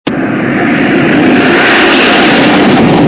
BSG FX - Viper Engine 02 Fly by
BSG_FX-Viper_Engine_02_Fly_By.mp3